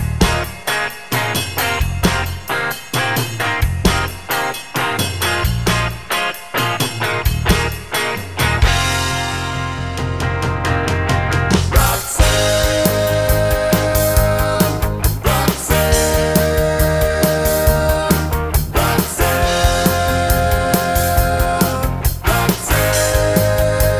Two Semitones Down Pop (1980s) 3:10 Buy £1.50